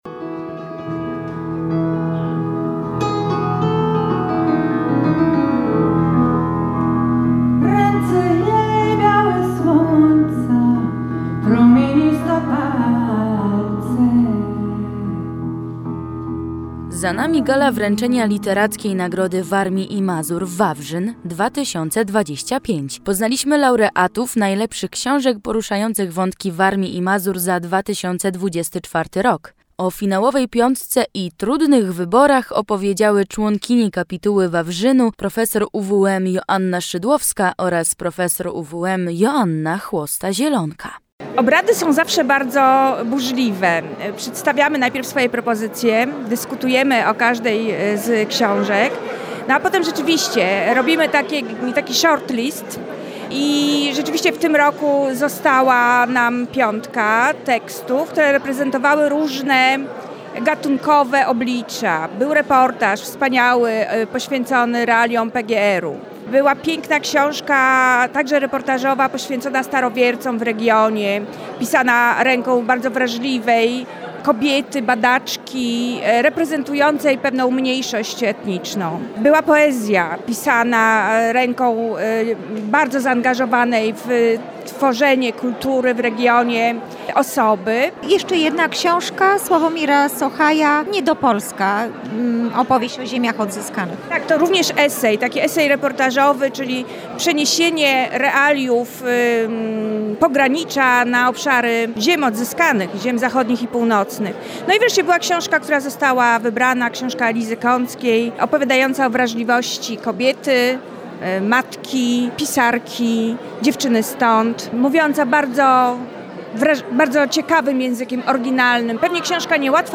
Relacja-GALA-WAWRZYN-2025.mp3